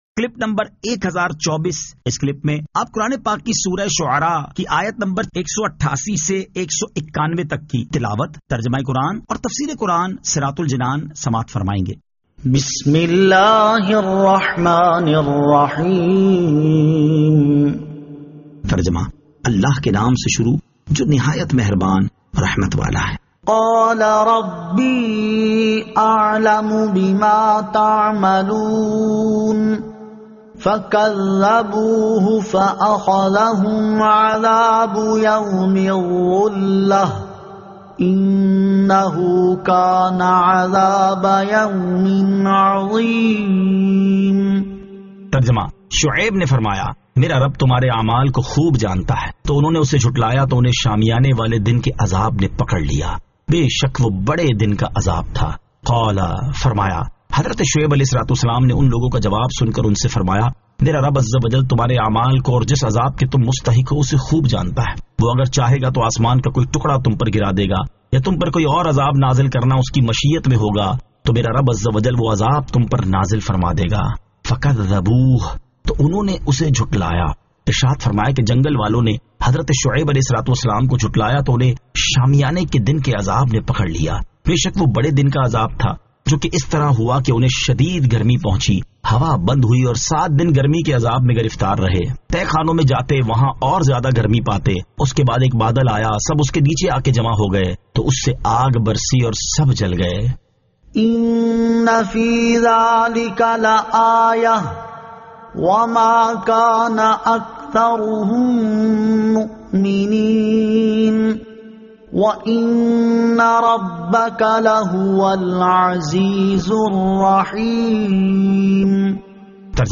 Surah Ash-Shu'ara 188 To 191 Tilawat , Tarjama , Tafseer